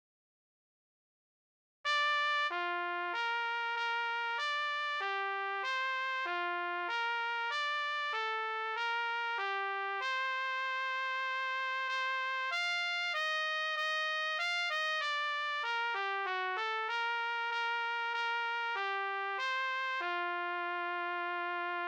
C=Harmony/Bass Part-for beginner players